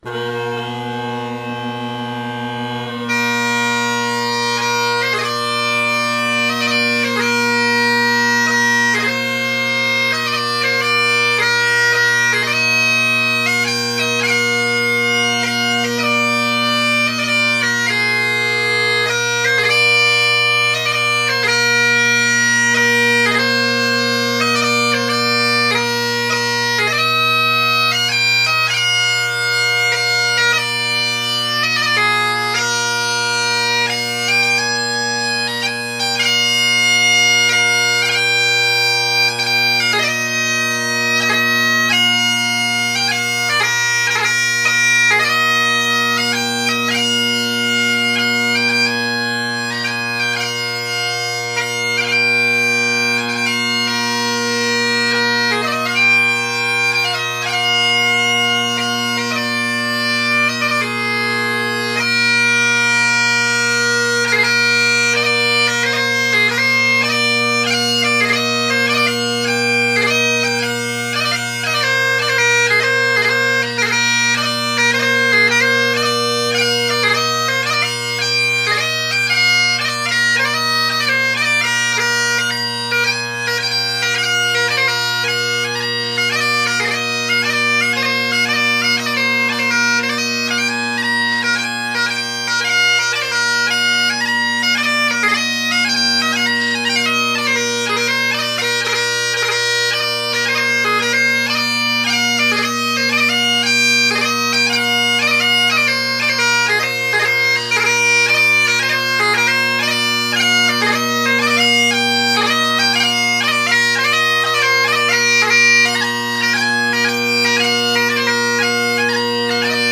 Great Highland Bagpipe Solo
Also, no one else was home so I got to play in a bigger room.